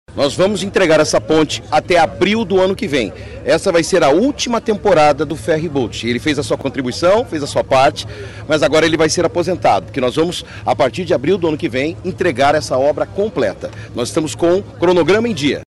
O secretário de Infraestrutura e Logística, Sandro Alex, disse que a obra está dentro do cronograma estipulado e será finalizada dentro de aproximadamente um ano.